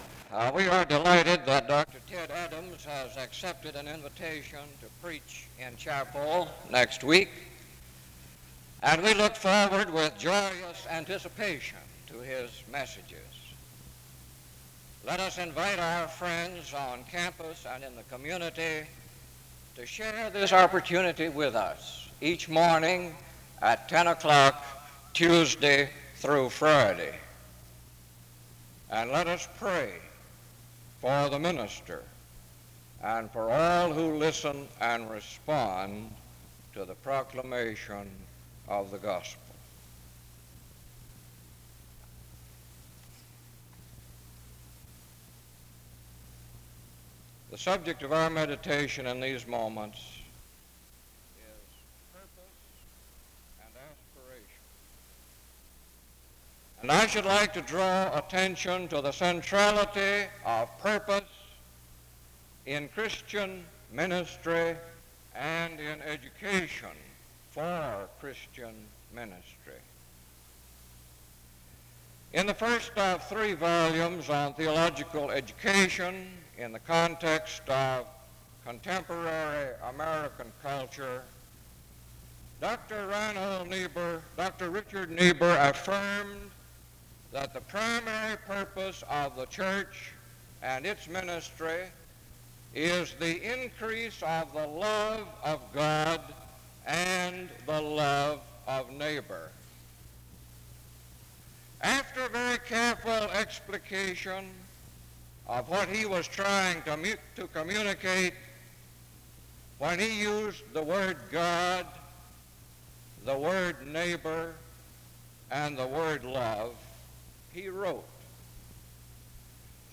The service begins with an announcement from 0:00-0:40.